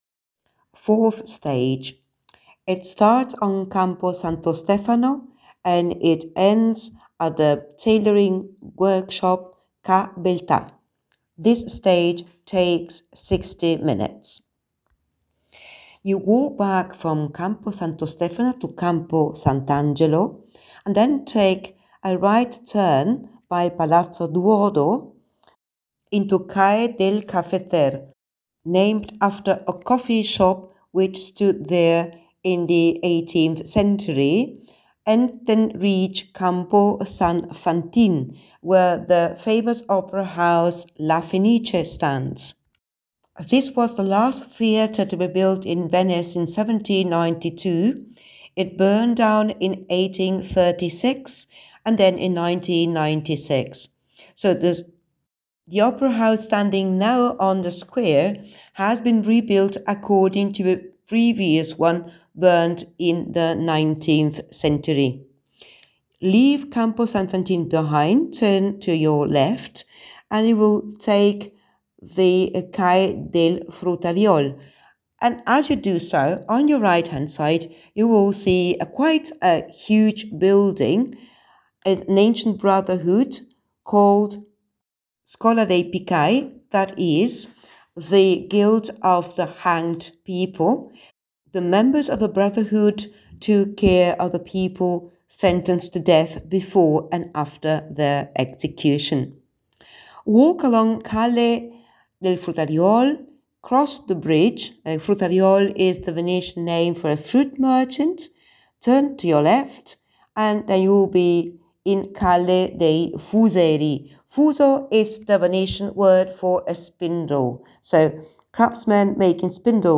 Listen to the audio tour